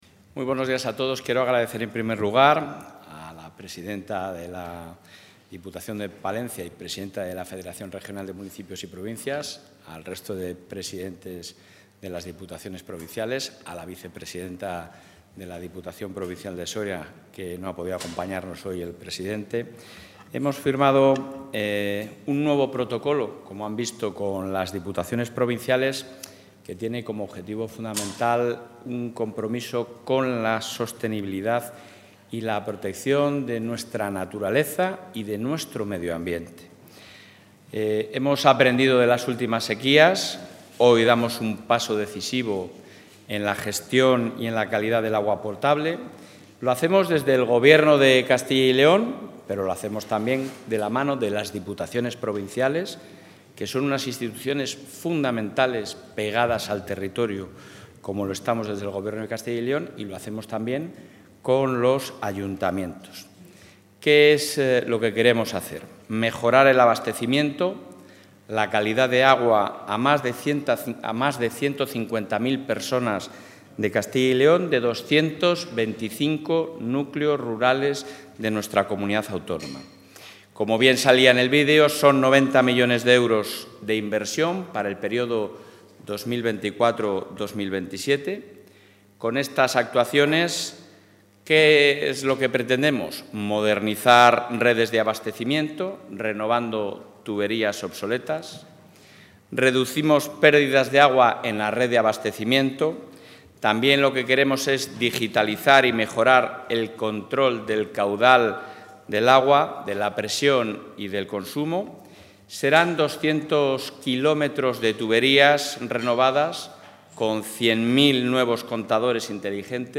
Intervención del portavoz.